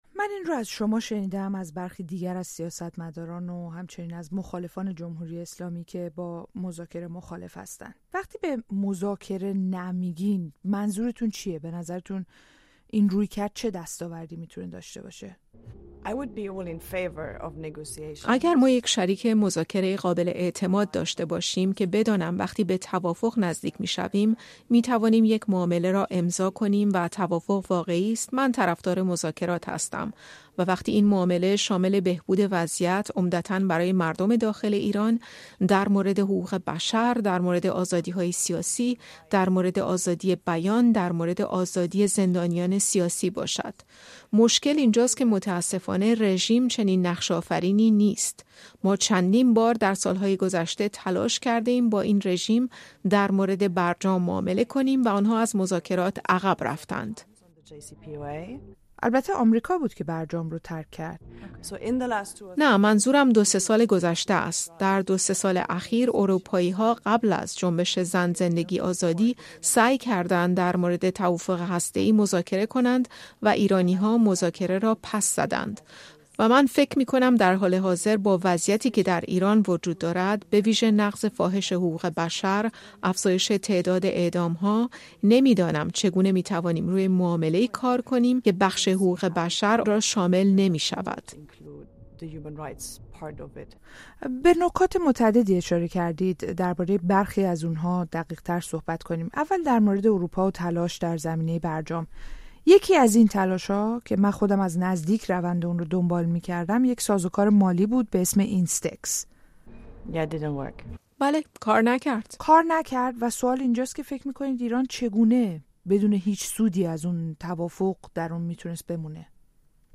در گفت‌وگو با هانا نویمان رئیس هیئت روابط با ایران در پارلمان اروپا موضوع روابط ایران و اتحادیه اروپا و چالش‌ها و فرصت‌های آن را بررسی کرده‌ایم.